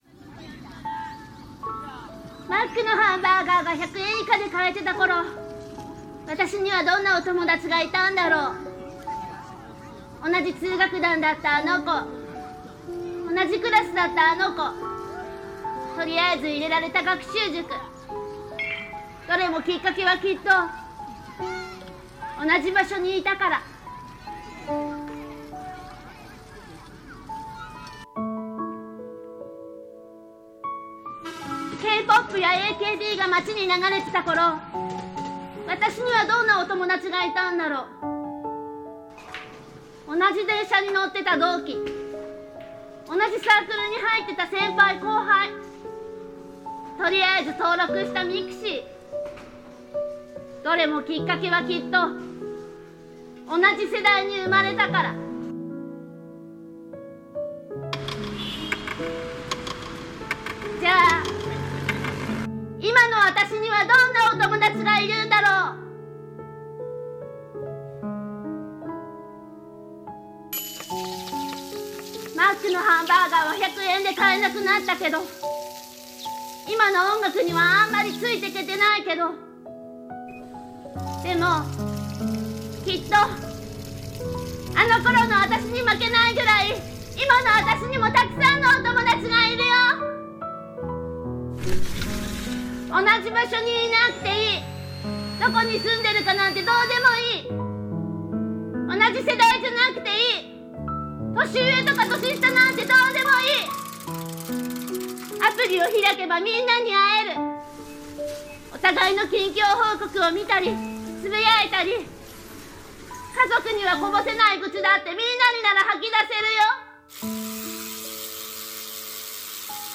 【朗読】